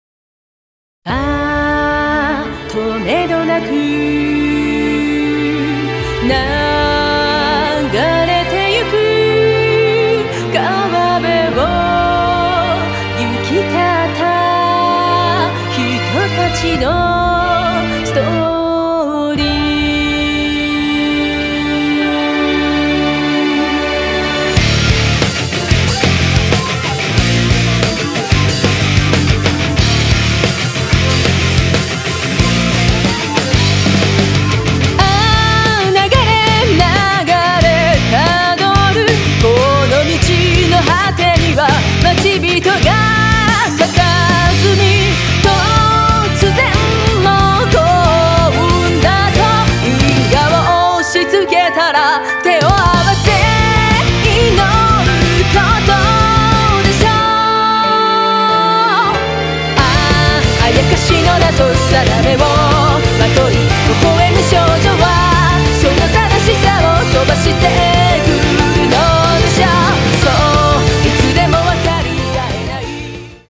クロスフェードデモ
ポップ＆ロックの東方フルボーカルアルバムがここに完成！